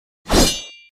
Attack.mp3